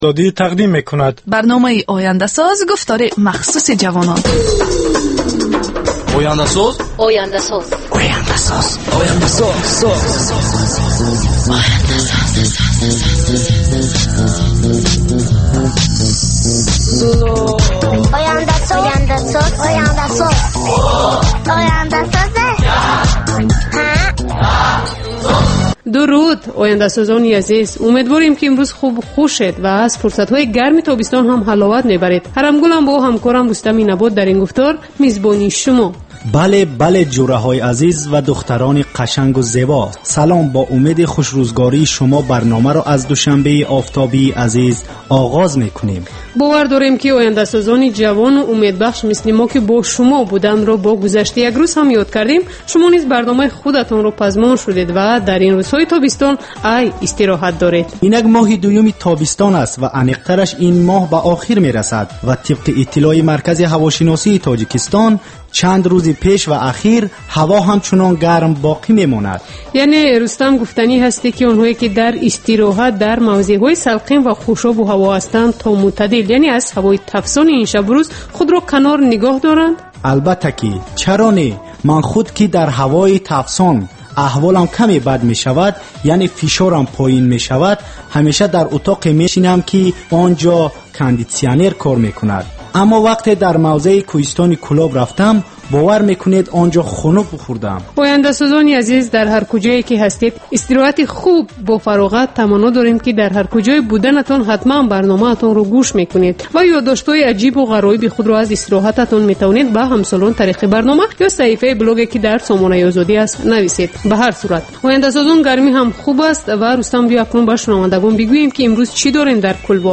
Бар илова, дар ин гуфтор таронаҳои ҷаззоб ва мусоҳибаҳои ҳунармандон тақдим мешавад.